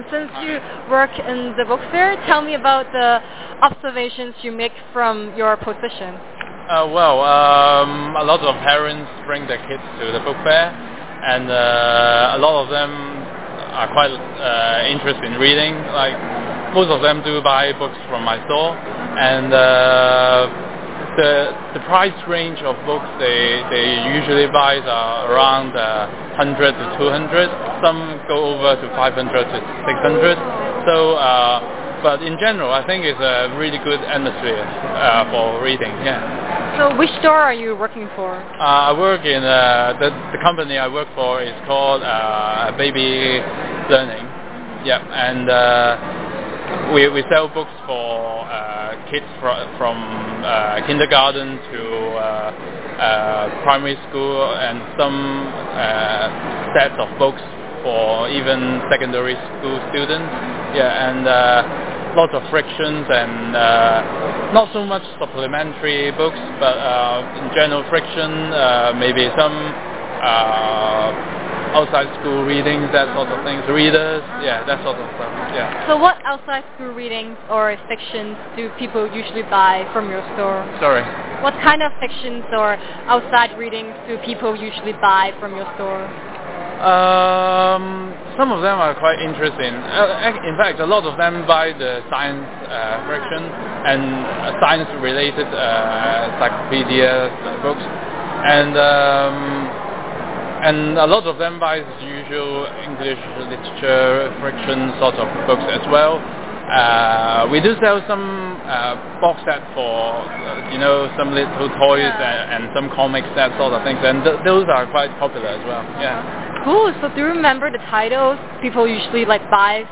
A Book Fair vendor says he likes sci-fi and will read non-stop until he finishes a book. He says sci-fi is less about learning and more about excitement. He has read Star Trek and Harry Potter.